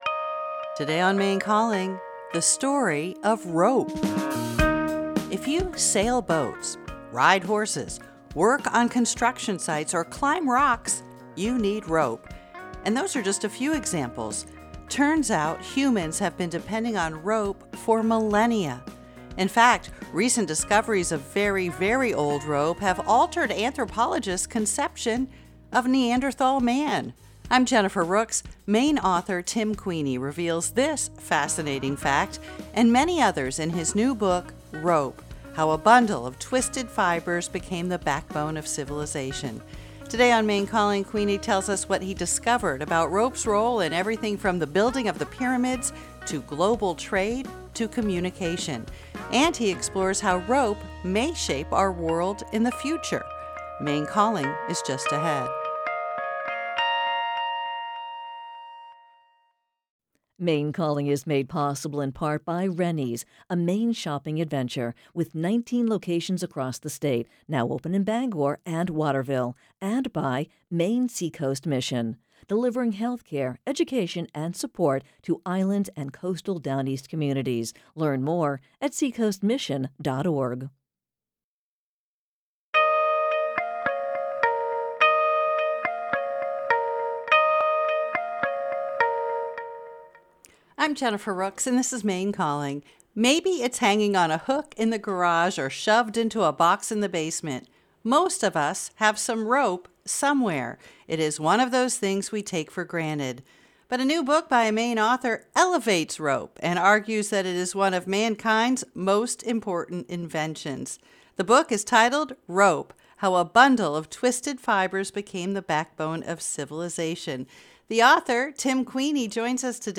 Maine Calling is a live, call-in radio program offering enlightening and engaging conversations on a wide range of topics.